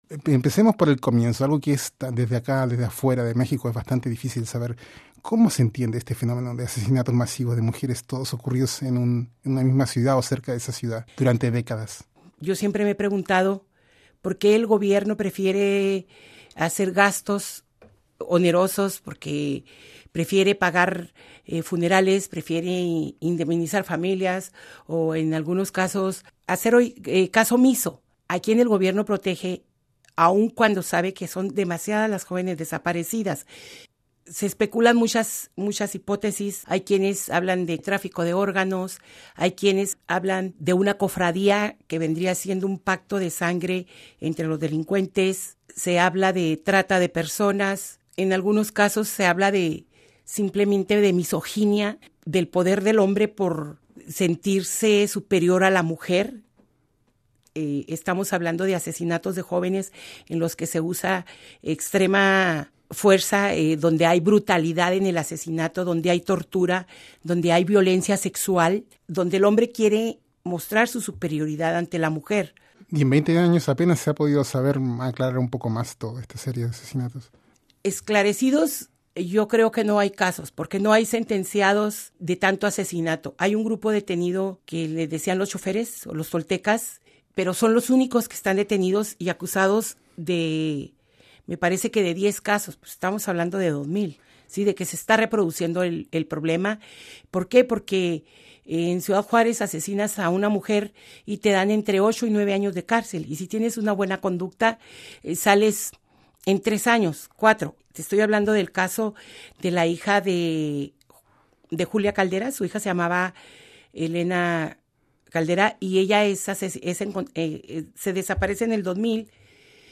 Buscando justicia en el infierno . Activistas mexicanas reciben el Premio Alice Salomon a la defensa de los derechos de la mujer. Entrevista de radio en Estación Sur, Funkhaus Europa.